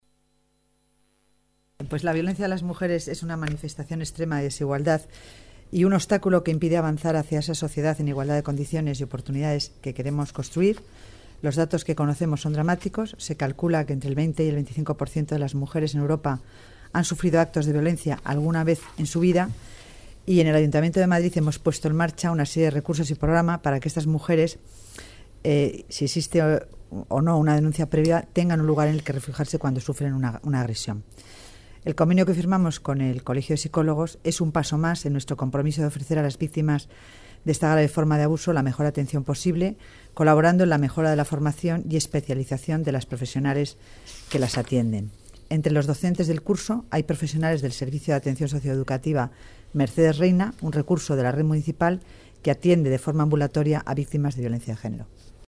Nueva ventana:Declaraciones Dancausa violencia de genero